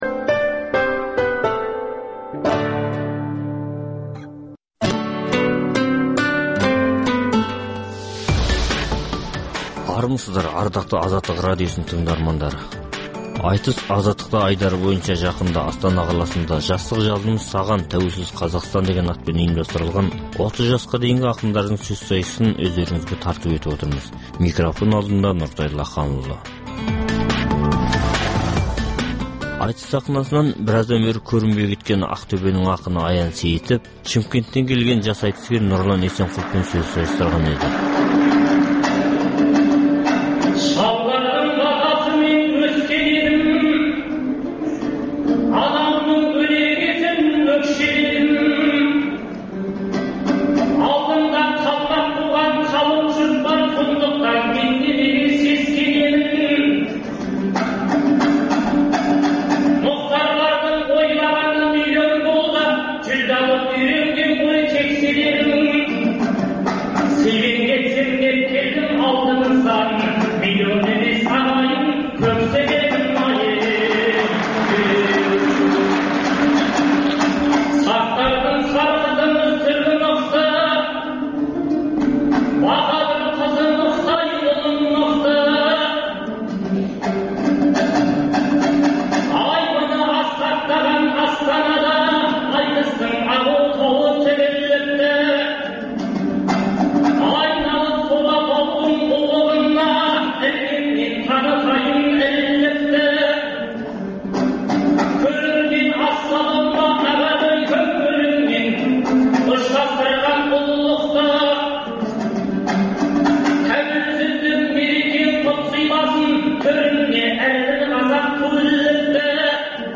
Қазақстанда әр уақытта өткізілетін ақындар айтысының толық нұсқасын ұсынамыз.